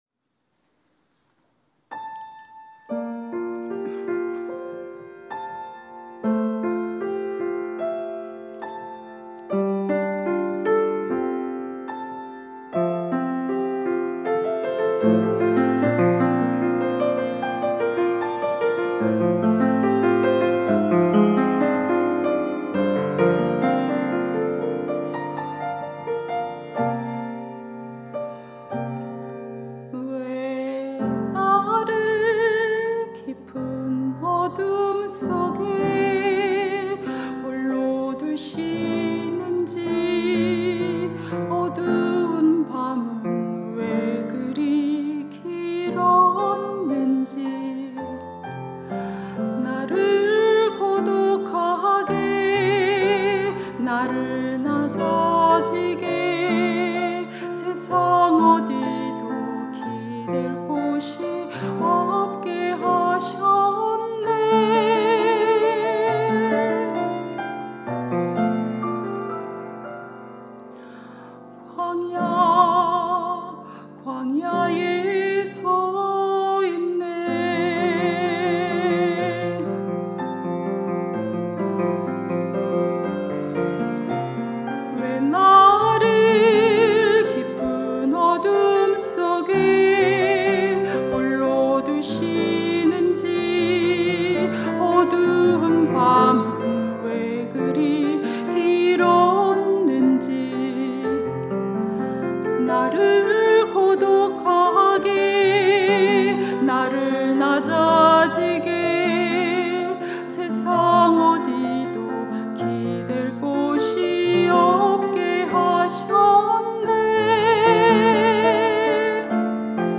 특송과 특주 - 광야를 지나며